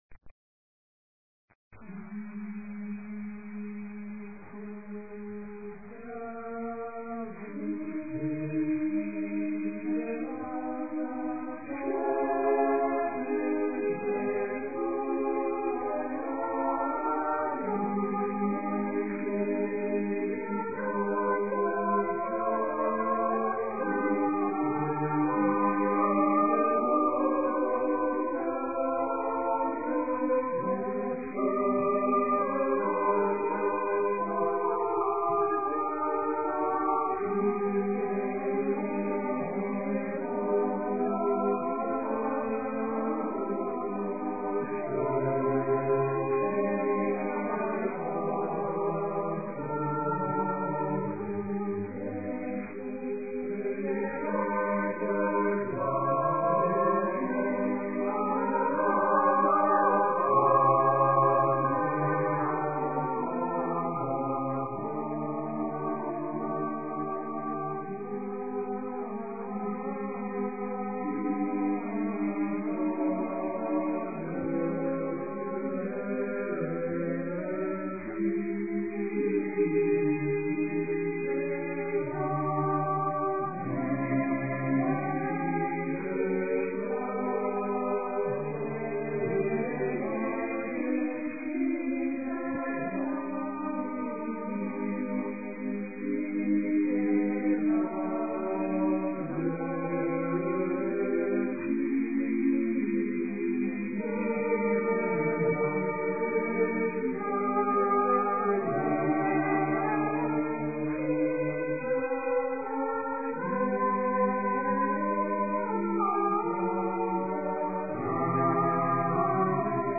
Play image ... 3 Mins 08 Seconds (187 KB 08 Kbits/second 12,000 Hz Mono), with an approx. loading time of 60 seconds (at about 3 KB/second).
(noisy)